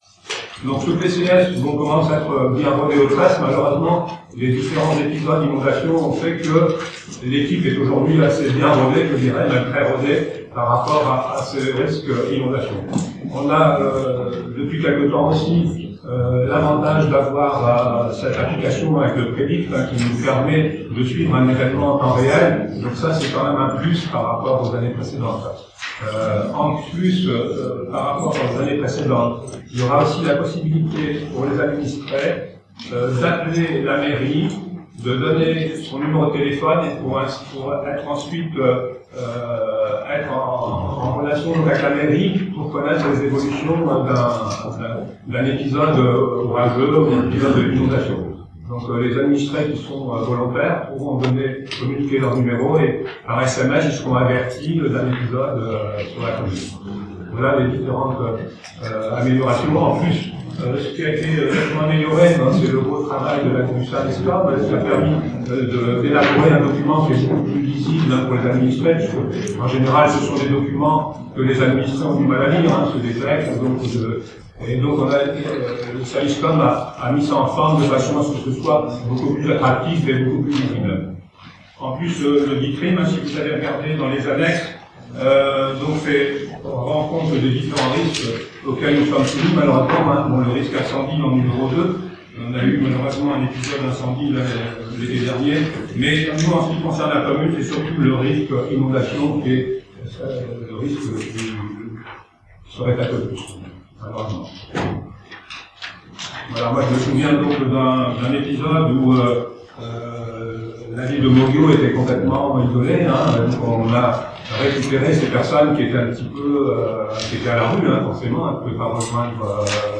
ENREGISTREMENT AUDIO DU CONSEIL MUNICIPAL :